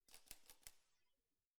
Part_Assembly_24.wav